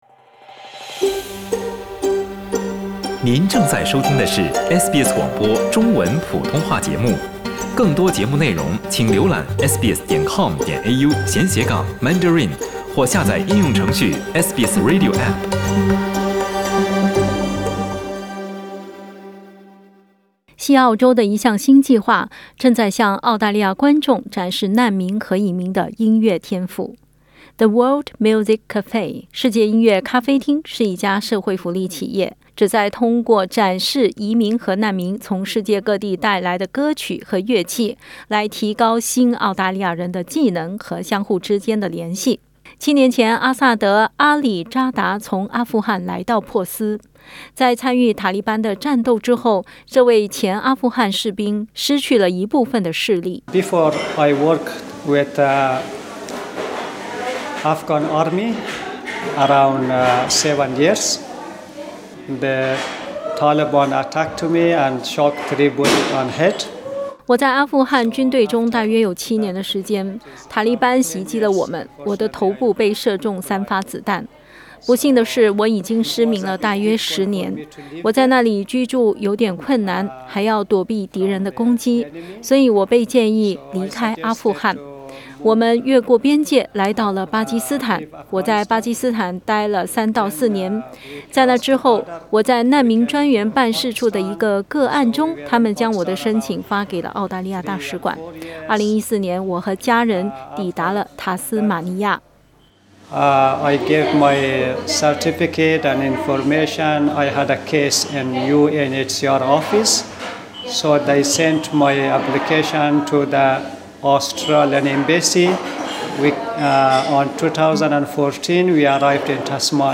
西澳州的一项新计划正在向澳大利亚观众展示难民和移民的音乐天赋。 世界音乐咖啡馆（The World Music Café）是一家社会福利企业，旨在通过庆祝他们从世界各地带来的歌曲和乐器来提高新澳大利亚人的技能和联系。 点击图片收听详细报道。